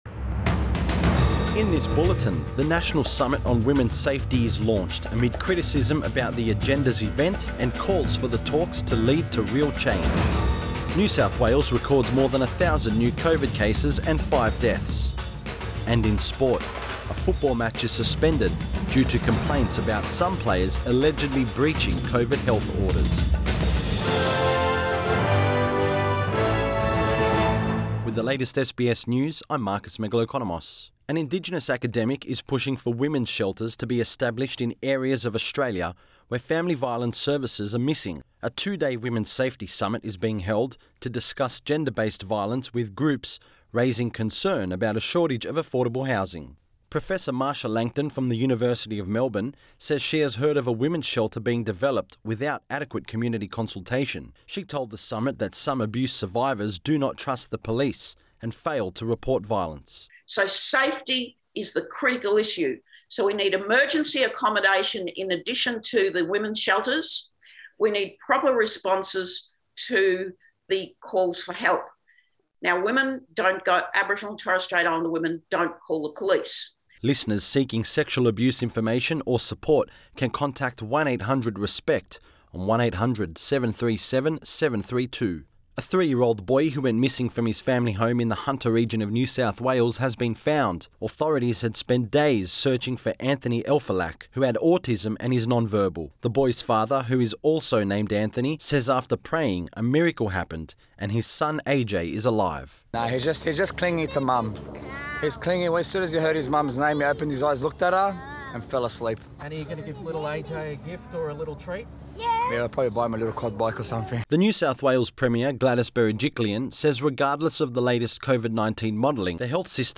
PM bulletin 6 September 2021